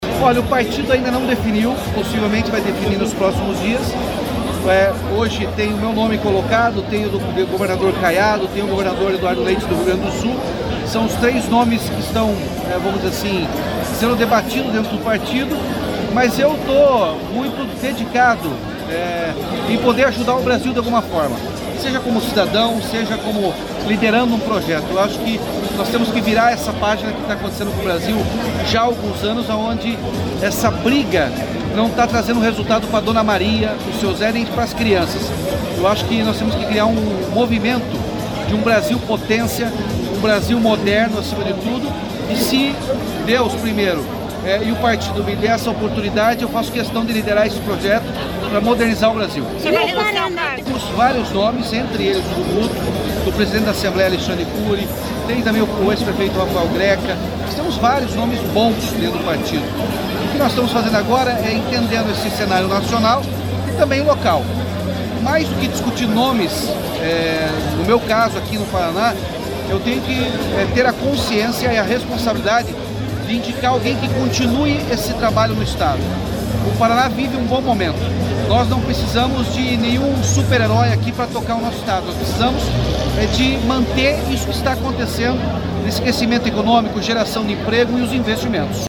O governador Ratinho Junior está em Maringá nesta quinta-feira (5) para a inauguração da obra do Trevo do Catuaí. Durante coletiva de imprensa, o governador falou sobre política.